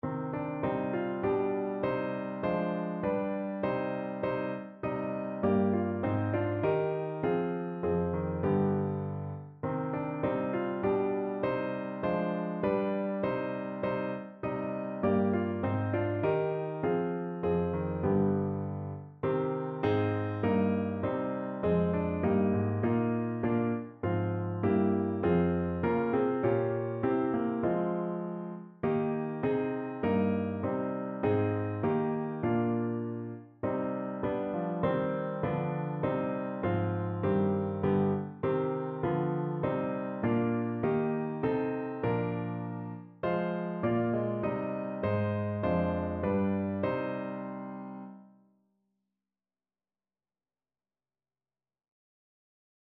Notensatz 1 (4 Stimmen gemischt)